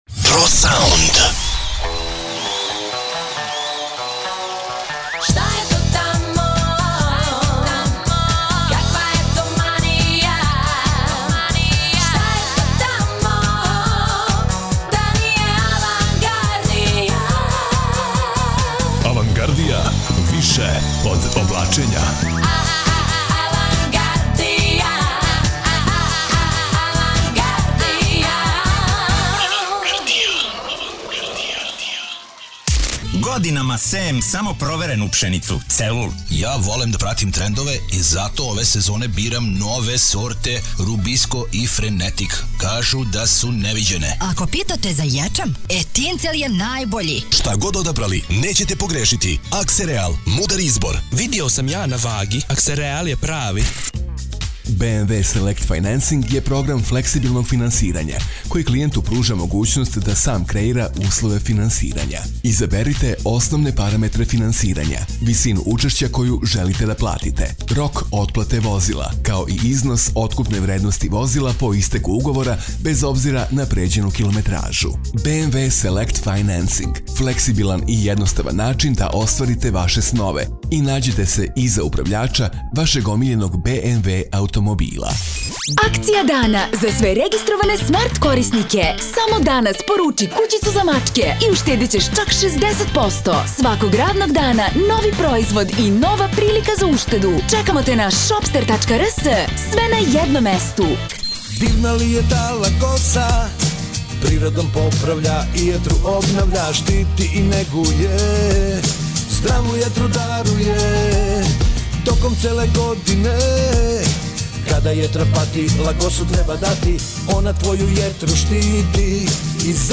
RADIO REKLAME